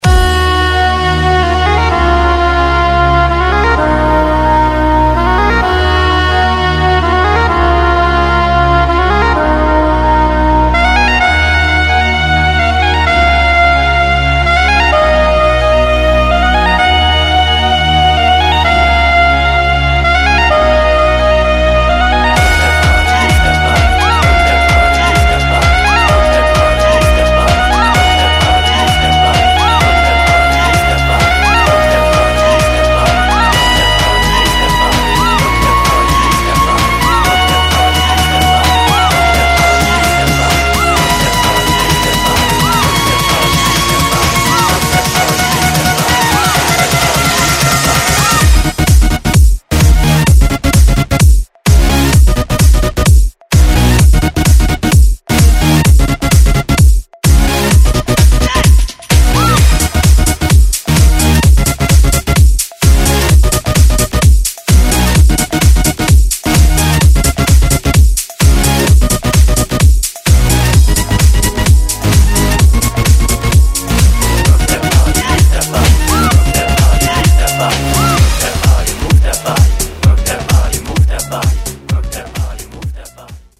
• Качество: 128, Stereo
только музыка без слов